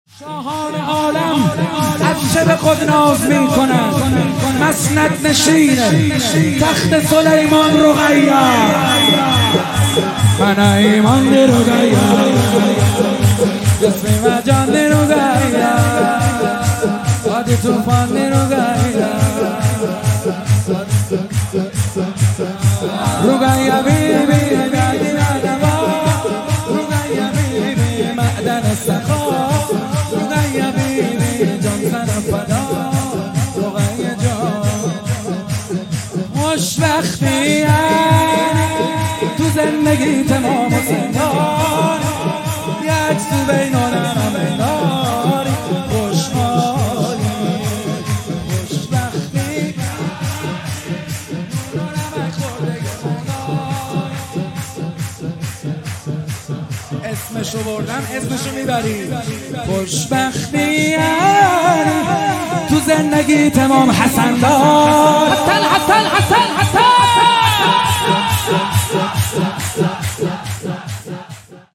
کربلایی محمد حسین حدادیان | ولادت حضرت رقیه (س) | هیئت جوانان سیدالشهدا(ع)تهران 3 اردیبهشت 98 | پلان 3